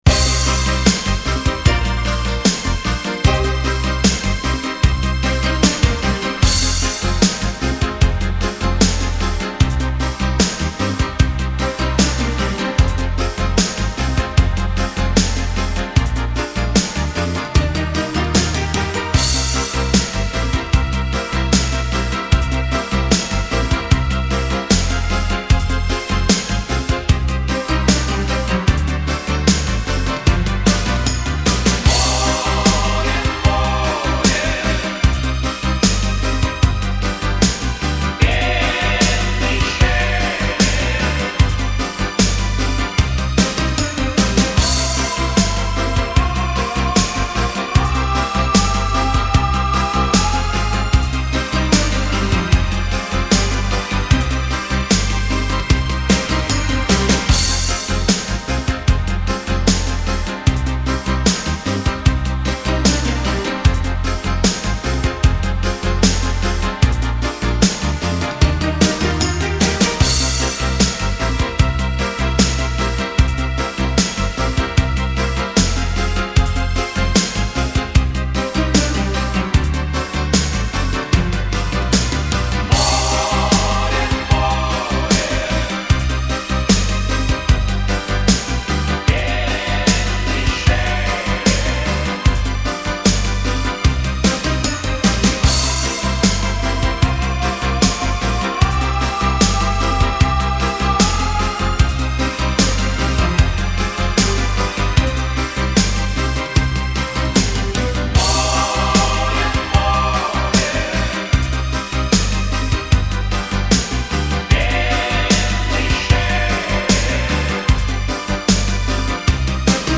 Похоже на мелодии для Караоке.